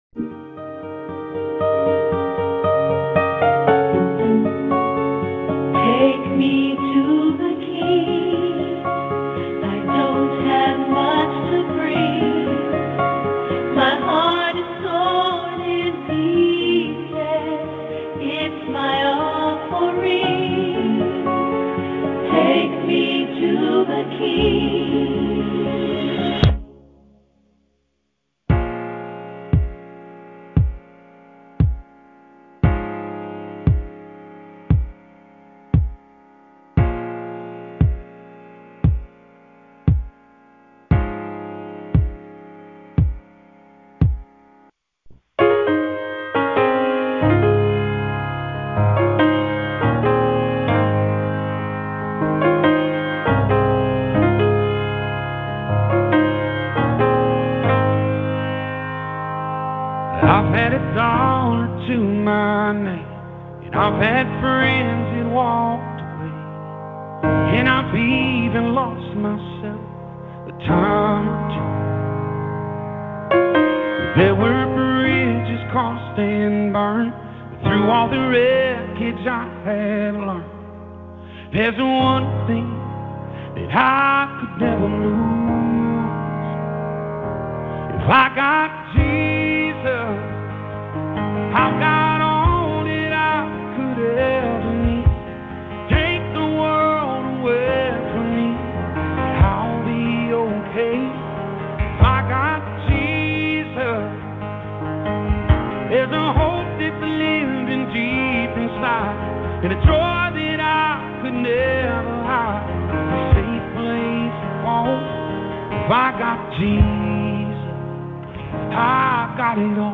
Powerful sermon!